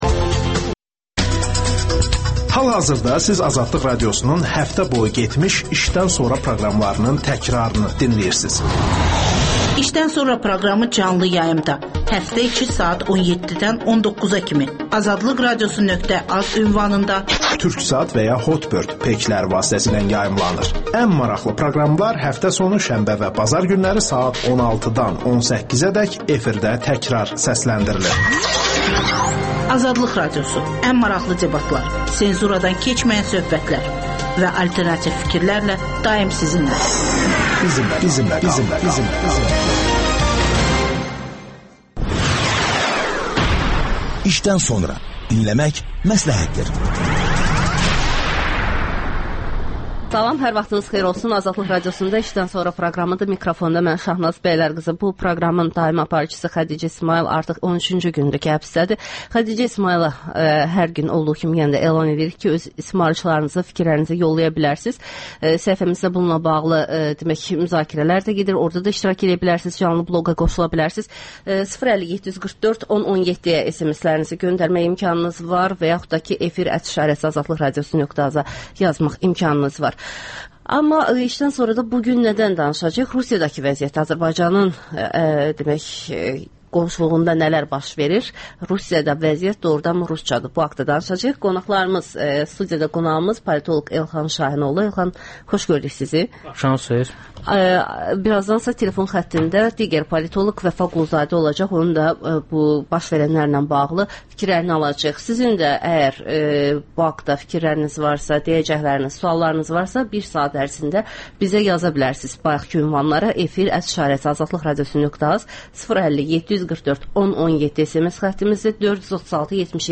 AzadlıqRadiosunun müxbirləri canlı efirdə ölkədən və dünyadan hazırlanmış xəbərləri diqqətə yetirirlər.